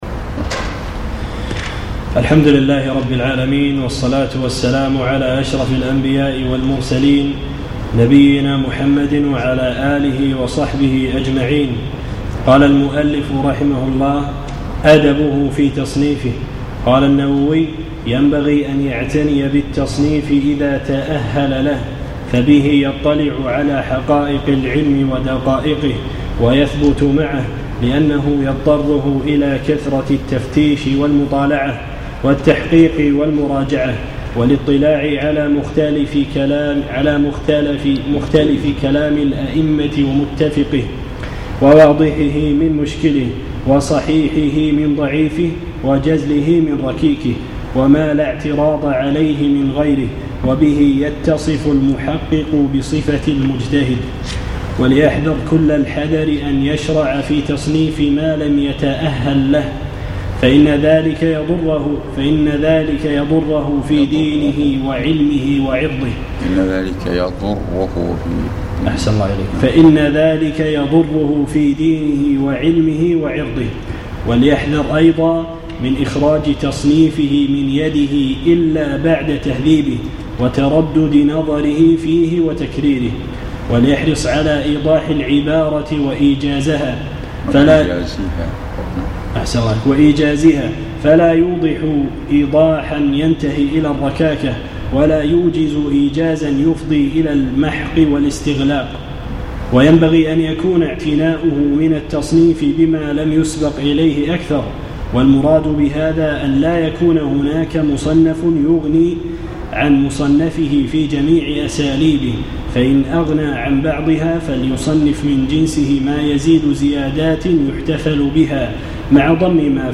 الأربعاء 22 جمادى الأولى 1437 الموافق 2 3 2016 مسجد الرويح الزهراء
الدرس الثالث